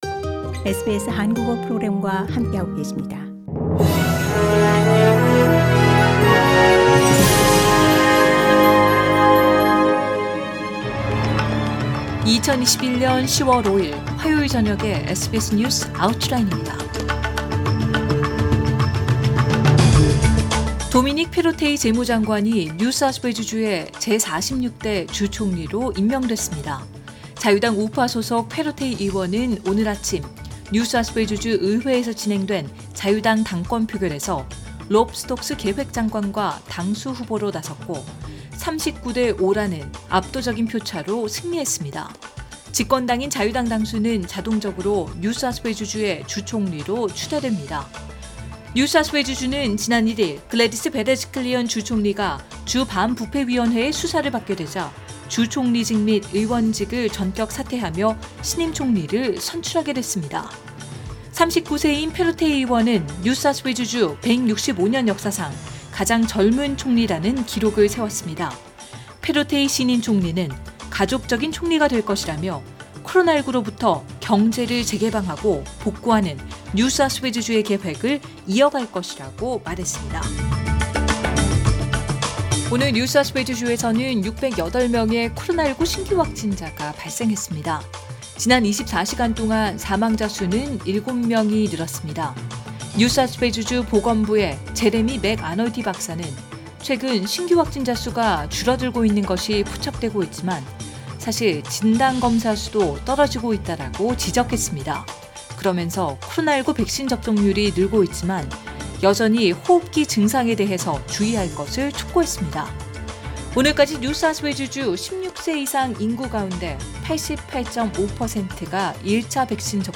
2021년 10월 5일 화요일 저녁의 SBS 뉴스 아우트라인입니다.